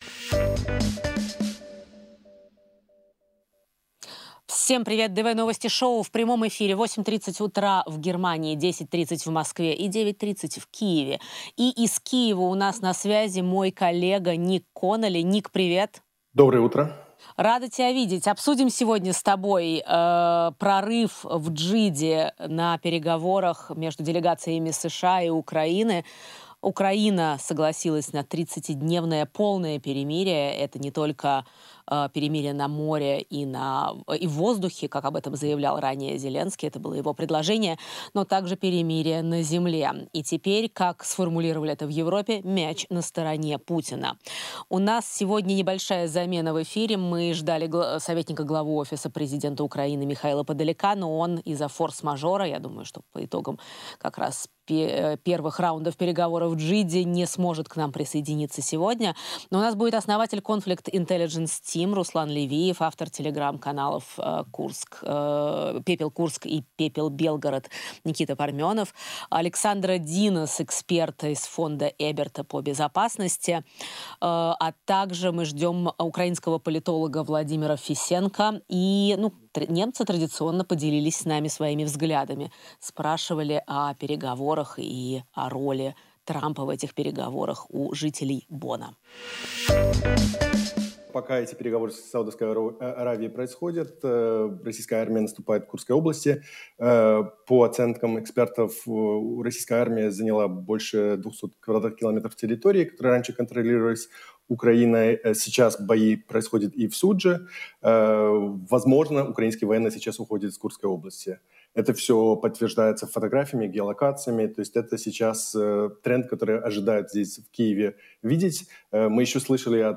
приглашенные ведущие в прямом эфире обсуждают со своими гостями и публикой самые главные события недели.
приглашенные ведущие обсуждают в течение часа со своими гостями в студии в Бонне события недели.